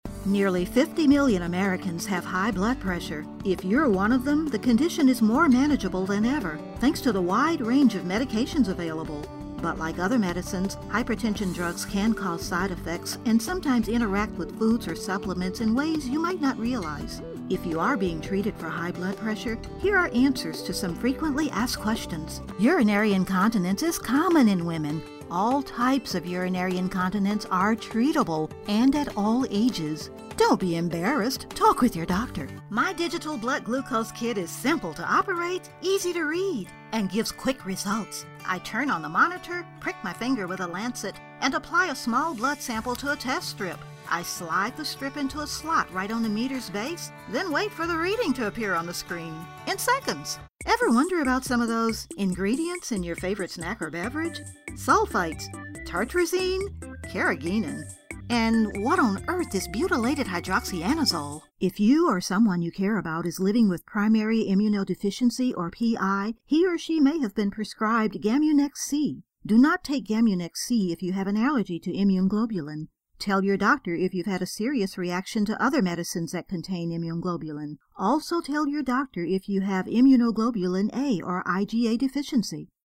Medium to upper register. Middle America, Southern "Dixie," African American (urban, not "street")
Sprechprobe: Sonstiges (Muttersprache):
Natural warmth, conversational, quirky, mature, corporate, cosmopolitan or folksy